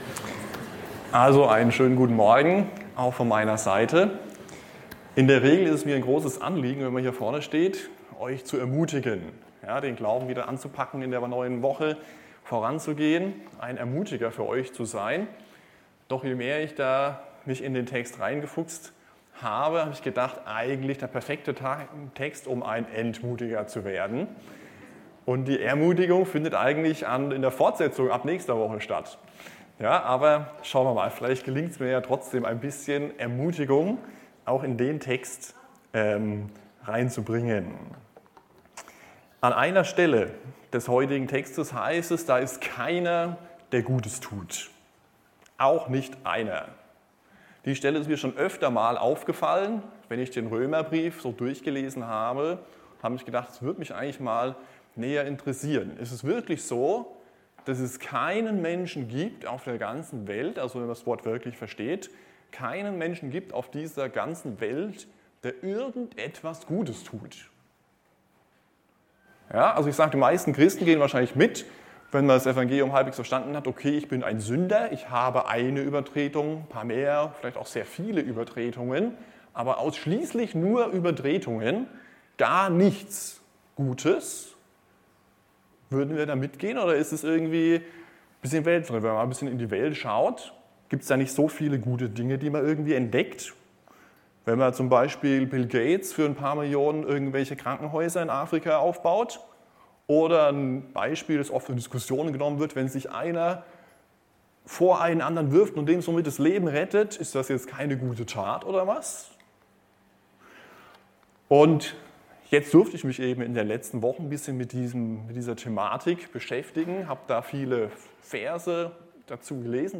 Heute predigte